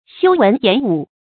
修文偃武 注音： ㄒㄧㄨ ㄨㄣˊ ㄧㄢˇ ㄨˇ 讀音讀法： 意思解釋： 提倡文教，停息武備。